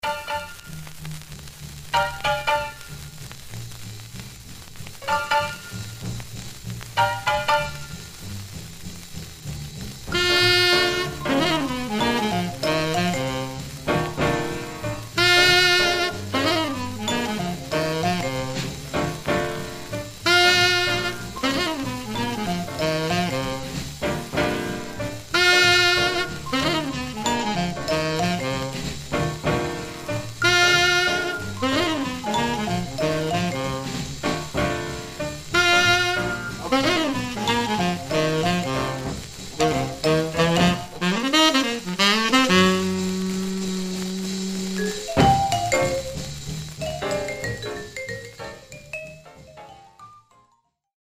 Male Gospel Group Condition: M-
Stereo/mono Mono